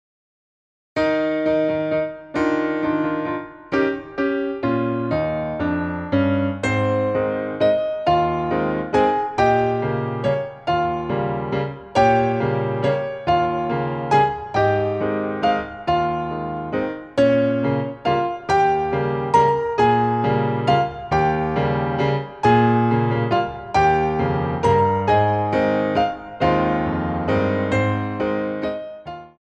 Warm Up